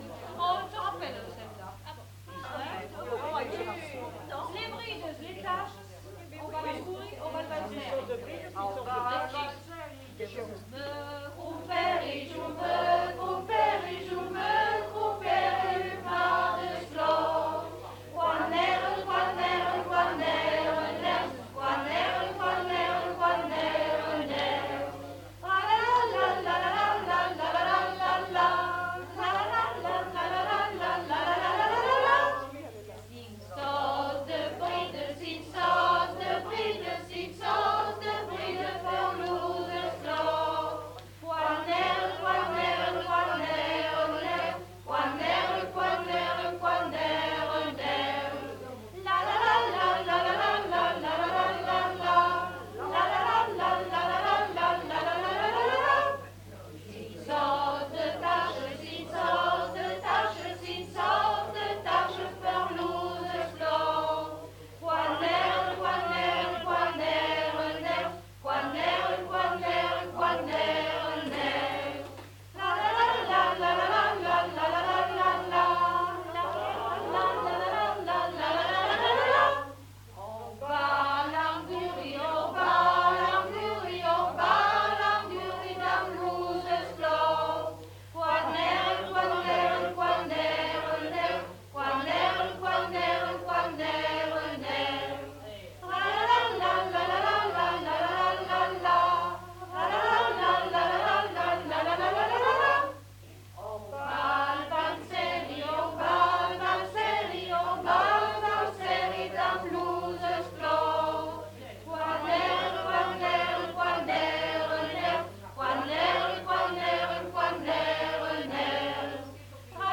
Aire culturelle : Grandes-Landes
Lieu : Salles
Genre : chant
Type de voix : voix de femme
Production du son : chanté
Danse : valse
Notes consultables : Interprété par un ensemble vocal.